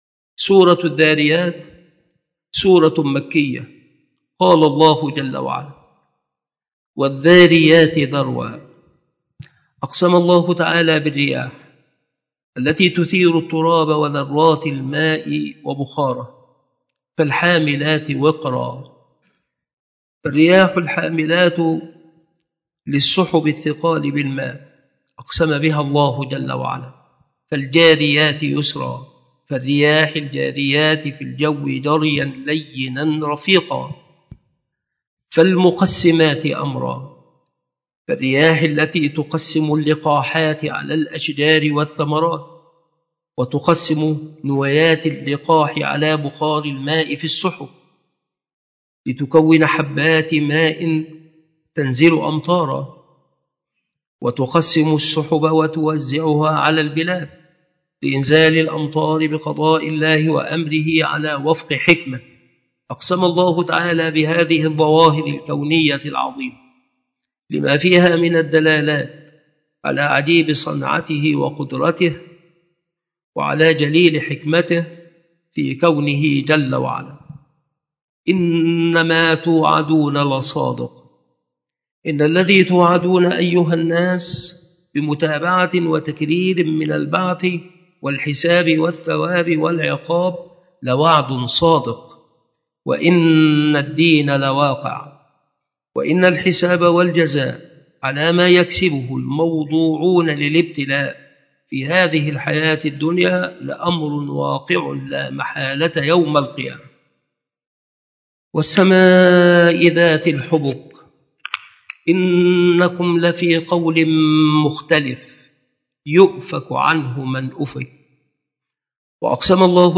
التصنيف التفسير
مكان إلقاء هذه المحاضرة بالمسجد الشرقي بسبك الأحد - أشمون - محافظة المنوفية - مصر